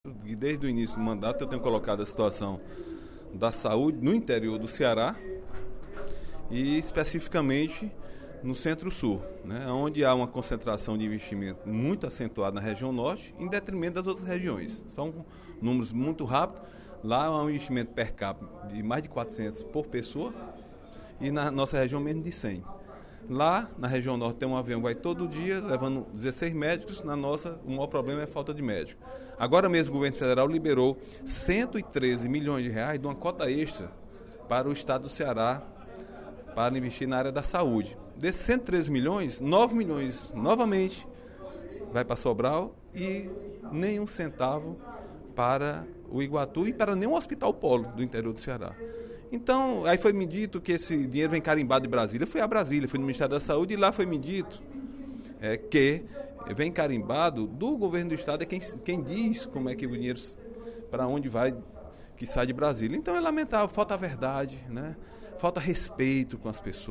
O deputado Agenor Neto (PMDB) fez pronunciamento, durante o primeiro expediente da sessão plenária desta quarta-feira (23/09), para criticar a falta de repasses por parte do Governo do Estado ao setor de saúde da região centro-sul do Estado, sobretudo para o Hospital Regional de Iguatu.